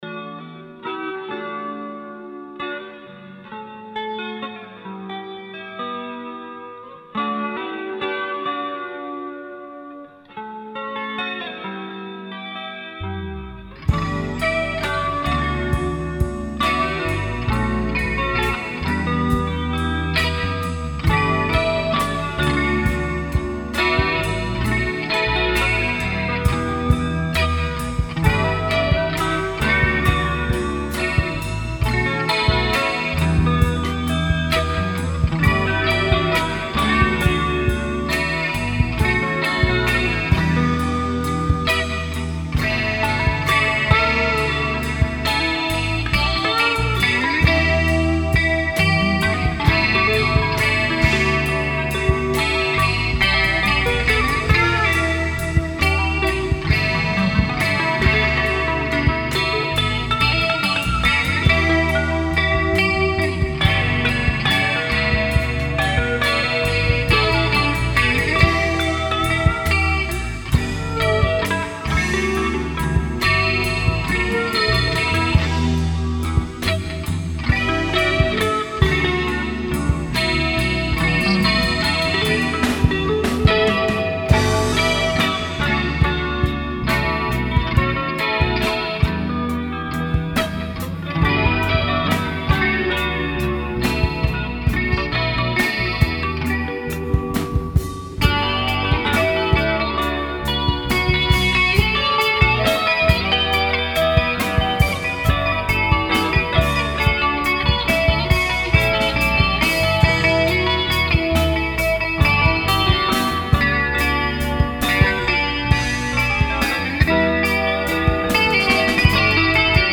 Recorded on 4-Track at the Moor Lane Farm rehearsal room